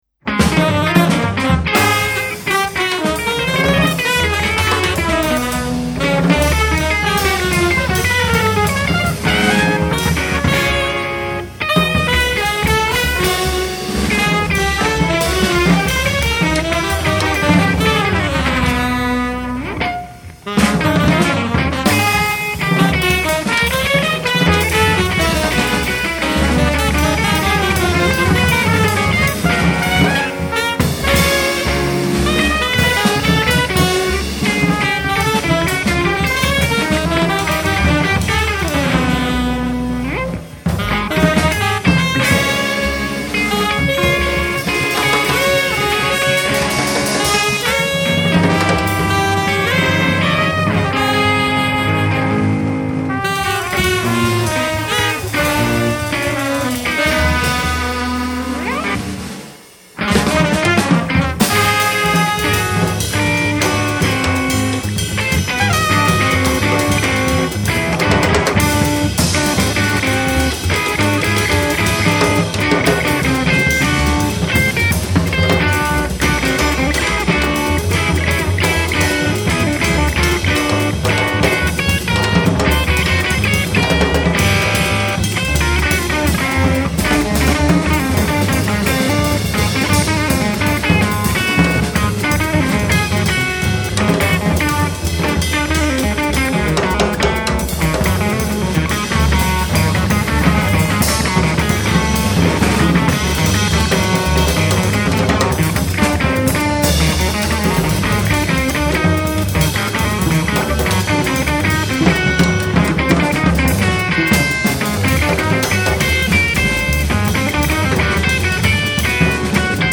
Recorded at Glenn Miller Café, Stockholm